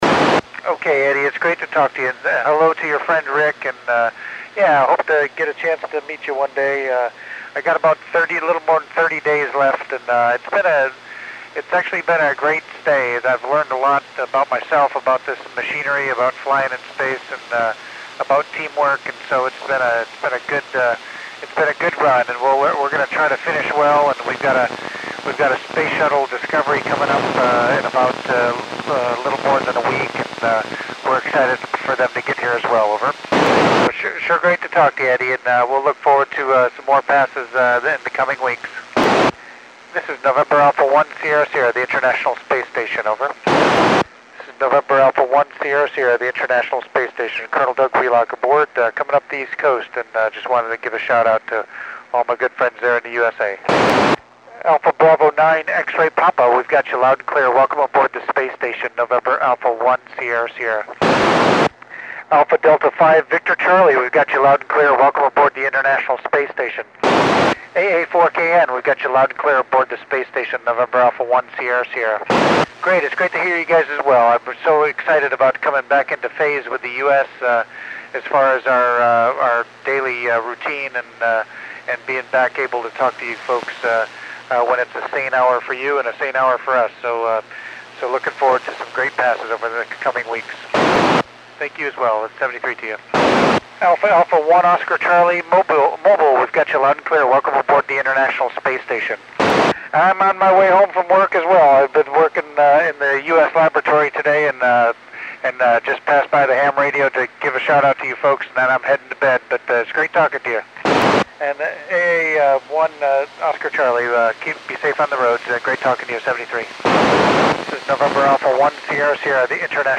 Col. Doug Wheelock (NA1SS) works U.S. and Canadian stations on 25 October 2010 at 2325 UTC.